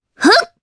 Requina-Vox_Attack1_jp.wav